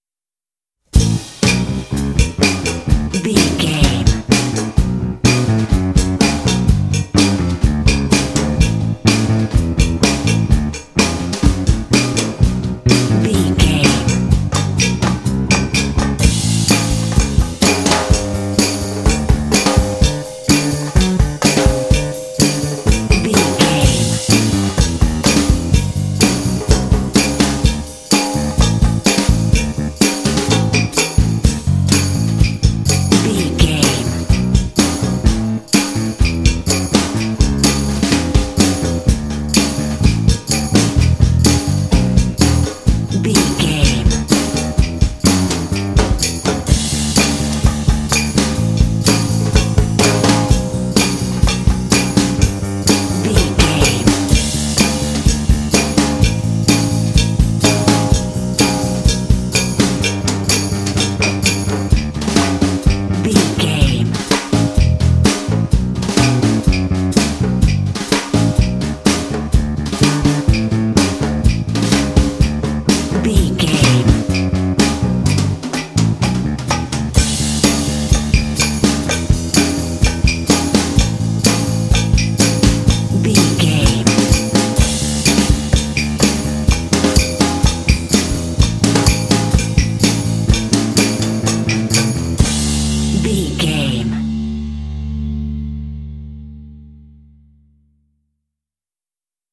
Ionian/Major
driving
cheerful/happy
lively
drums
percussion
bass guitar
rock
classic rock